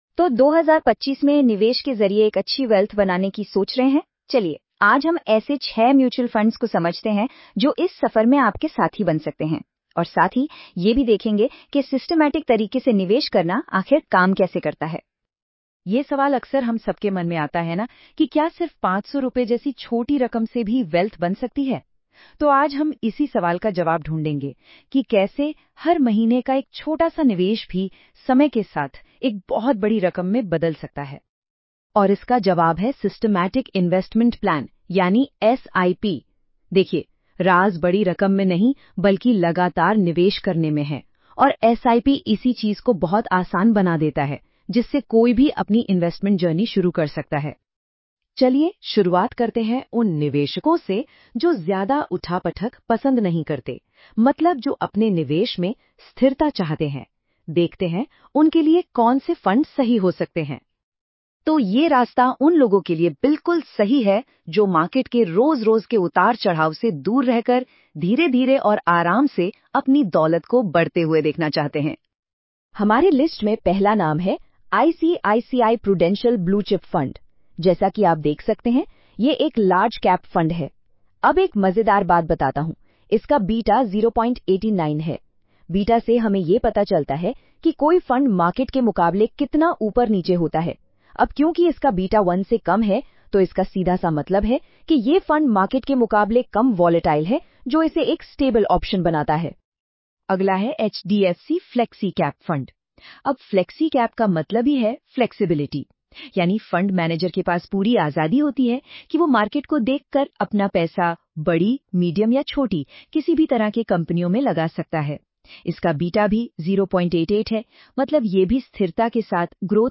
Before you start reading, listen to a short Hindi audio overview of this post — it gives you a quick idea about the topic in just a minute.